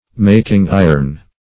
Making-iron \Mak"ing-i`ron\, n.